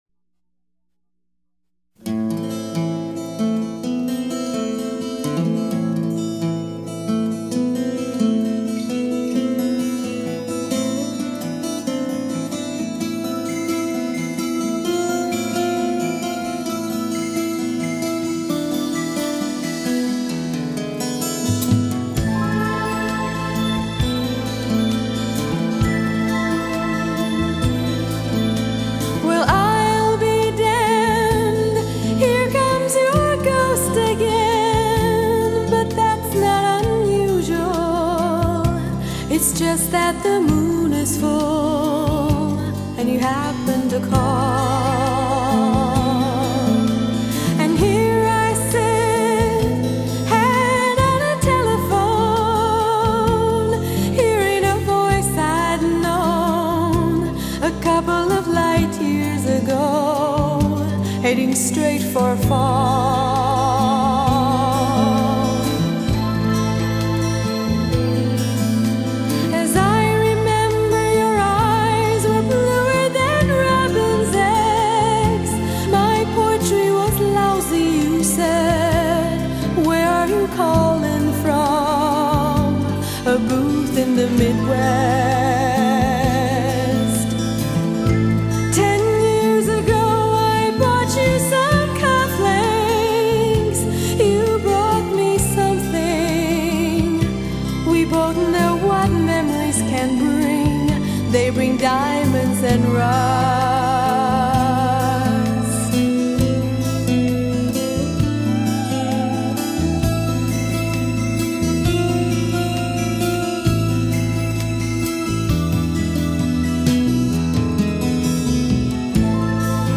风格流派：Pop